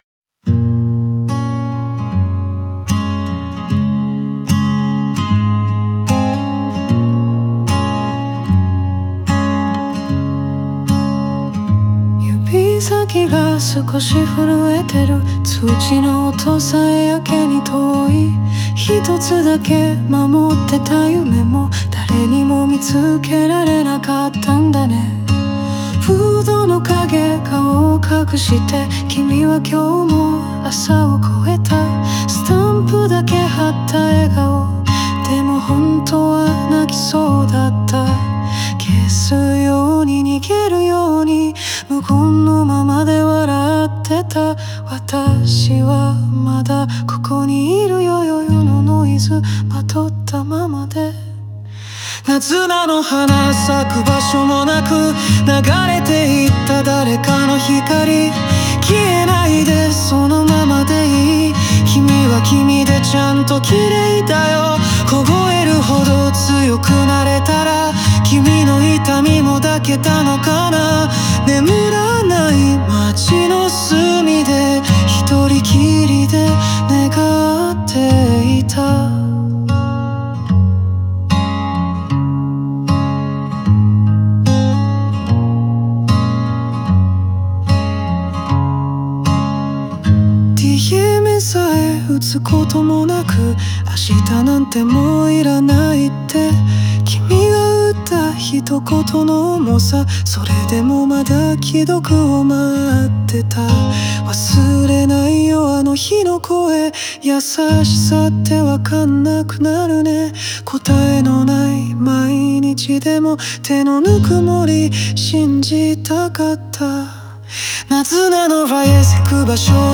オリジナル曲♪
控えめな音の構成と静かな歌声が、感情の揺れや内面の葛藤を際立たせ、聴く者の胸にそっと寄り添うような優しさを持つ作品です。